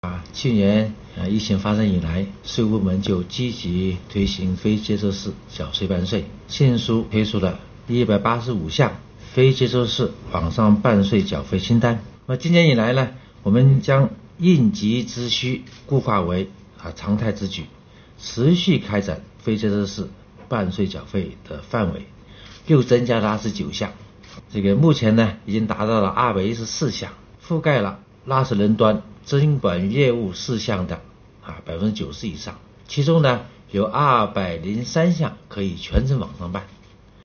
近日，国家税务总局举行新闻发布会，启动2021年“我为纳税人缴费人办实事暨便民办税春风行动”。国家税务总局总审计师饶立新介绍称，税务部门将持续拓展“非接触式”办税缴费范围。目前已拓展至214项，其中203项可全程网上办。